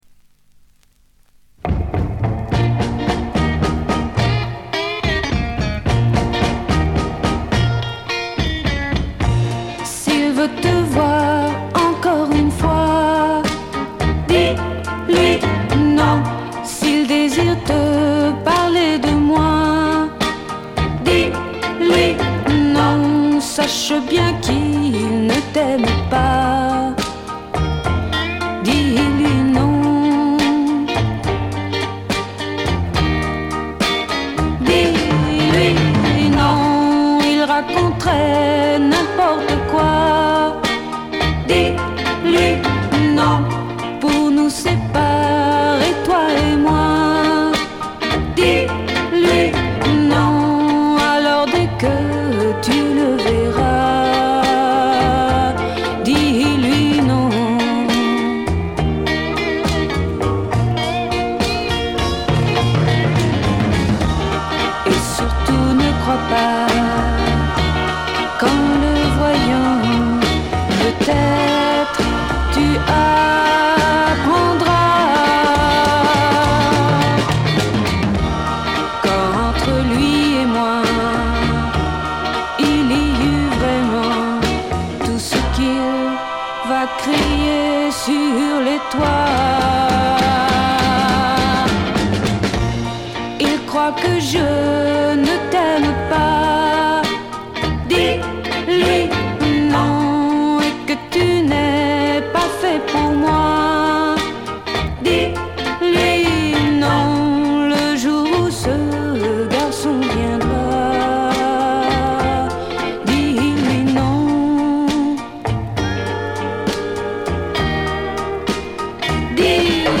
モノラル盤。
試聴曲は現品からの取り込み音源です。